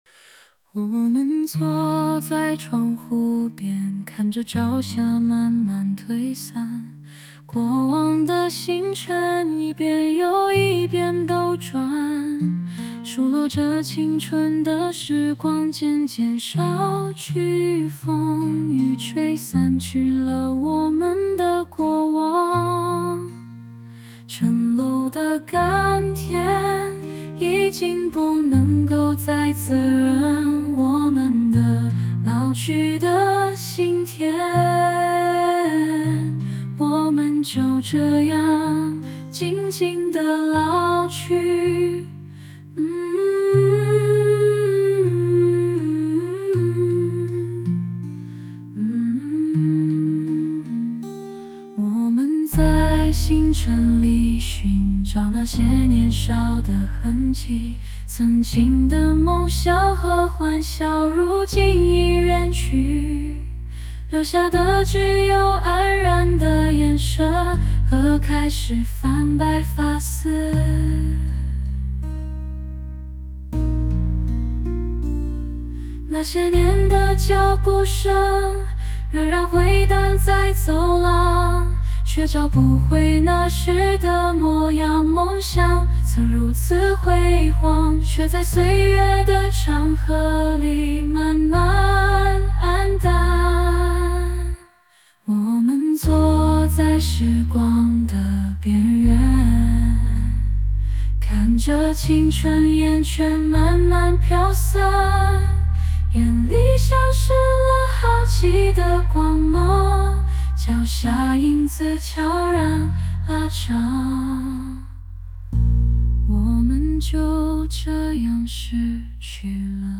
young male voice, guitar, pino, balladry, softly, catharsis, children hum with Interlude
本来想加点儿童的哼唱声的，"children hum with Interlude"没生效，后面有空再研究，最后生成的歌曲基本达到自己想要的效果和目的。